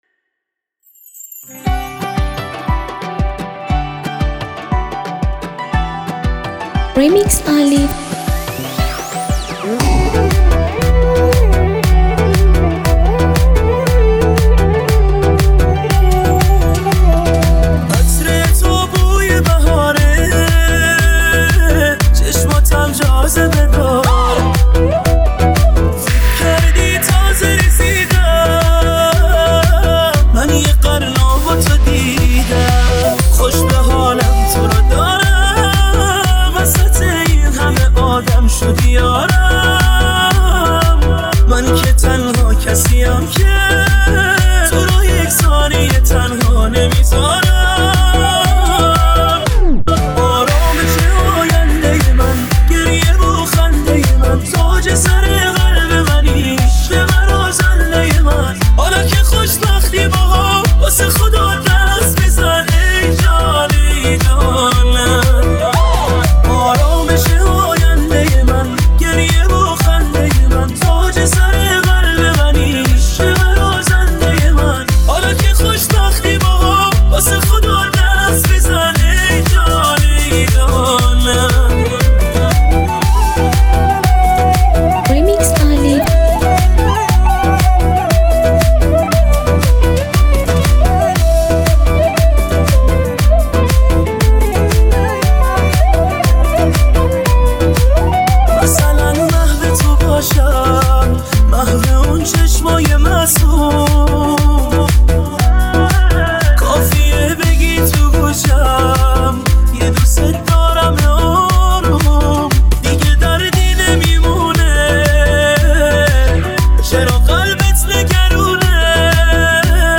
ریمیکس بیس دار